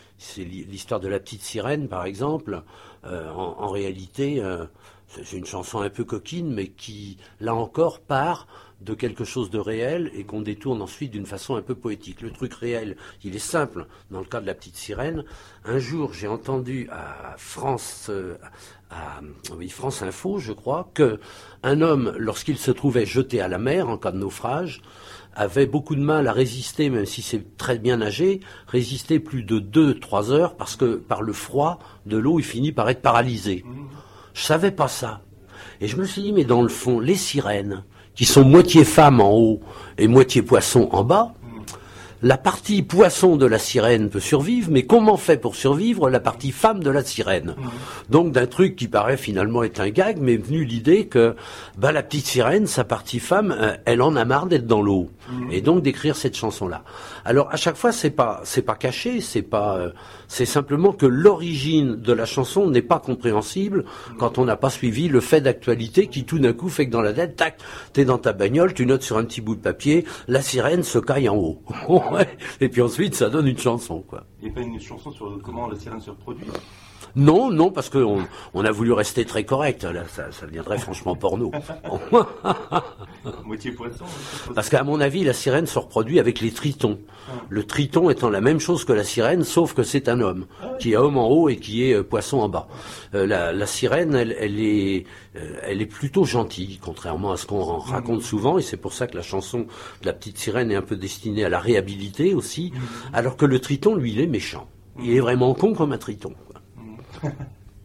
Abysses,l'INTERVIEW